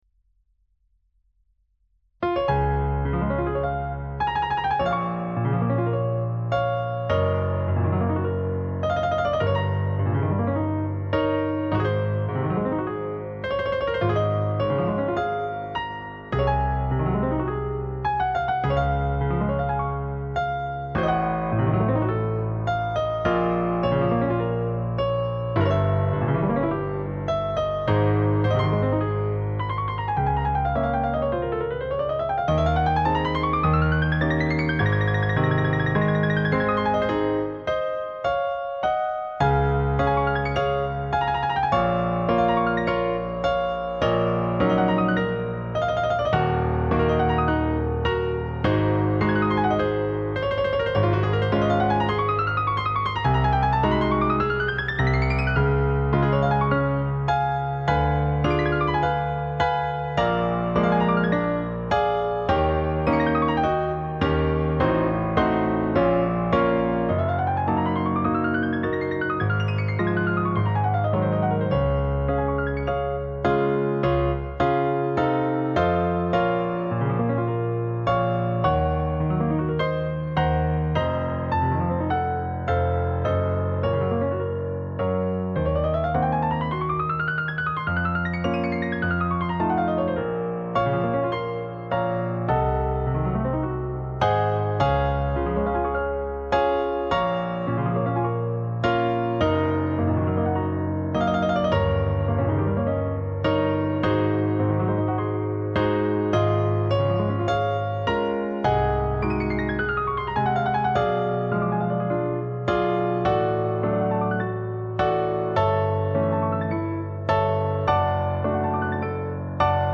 Piece: Allegro Moderato, Composer: Carl Czerny, Suite: Das moderne Klavierspiel Op.837 No.6